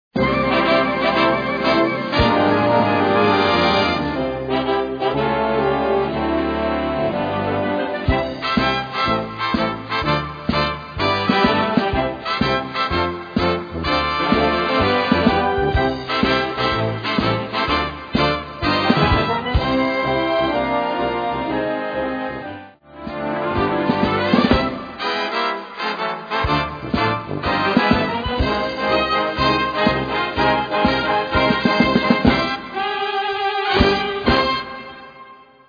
Gattung: Swing-Marsch
Besetzung: Blasorchester